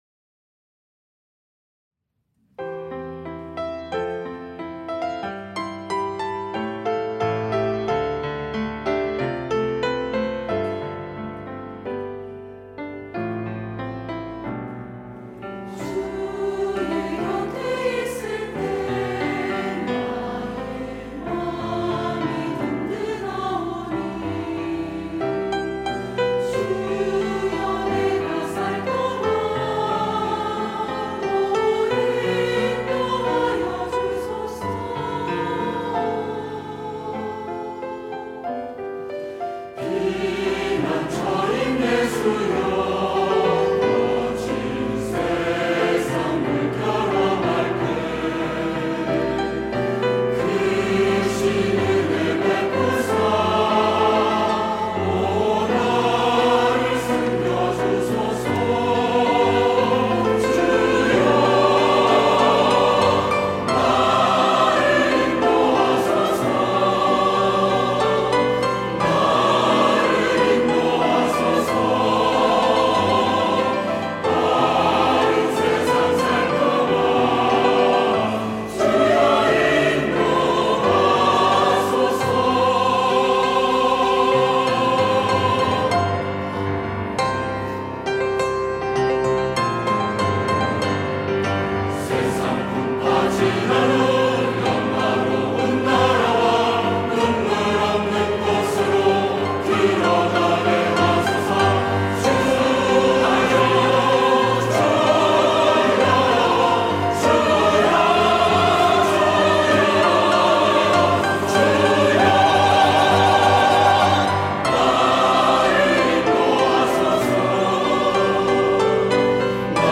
할렐루야(주일2부) - 주의 곁에 있을 때
찬양대